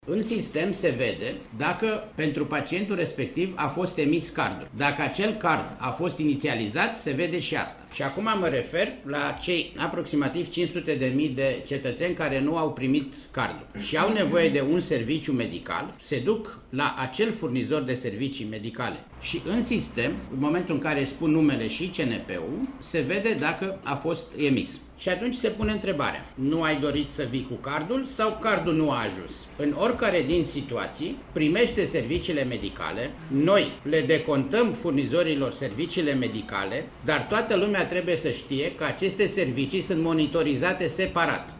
Preşedintele Casei Naţionale de Asigurări de Sănătate, Vasile Ciurchea: